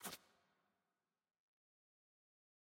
sfx_ui_map_panel_change.ogg